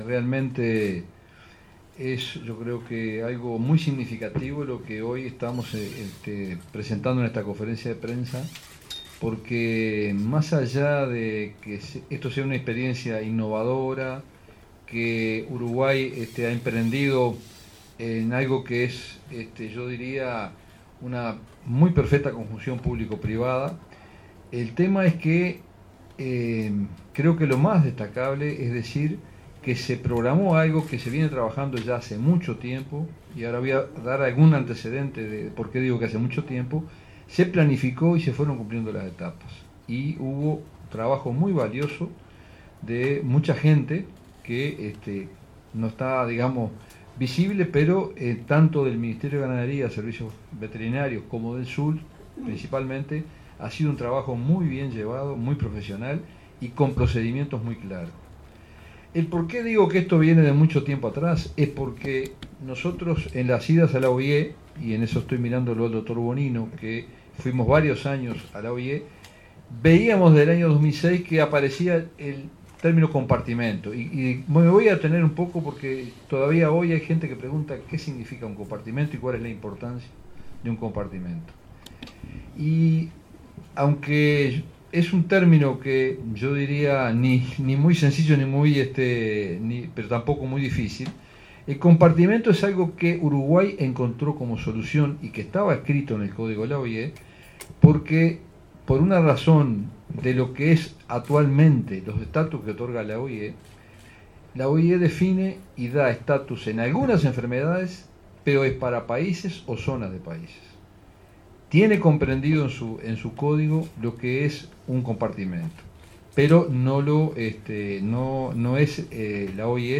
Director de Servicios Ganaderos Francisco Muzio. mp3. 6:07